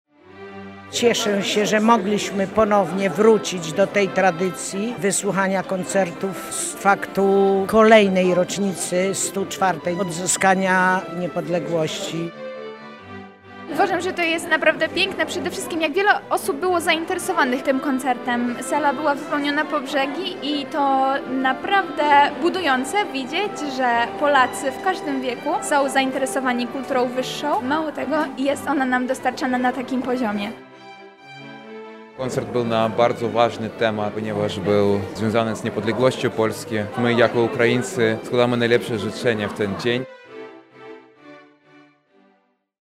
Obecni podzielili się swoimi wrażeniami z naszym reporterem: